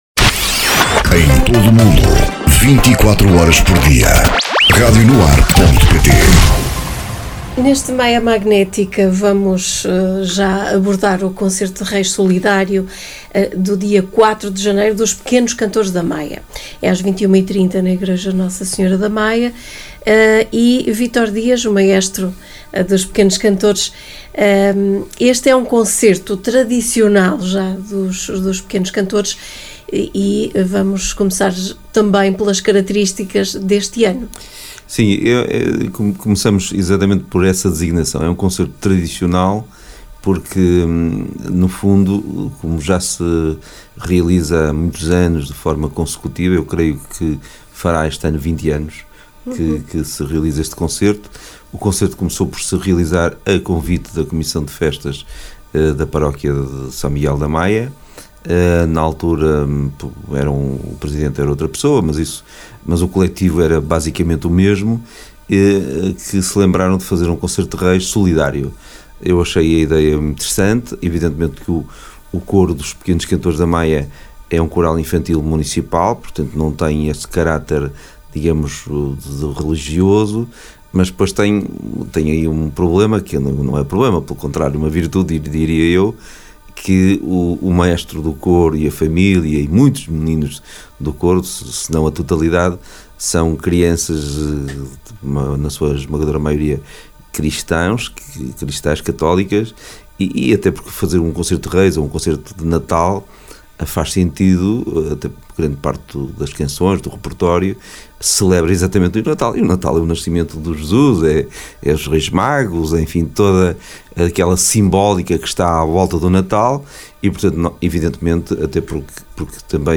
Entrevista
realizada na Rádio NoAr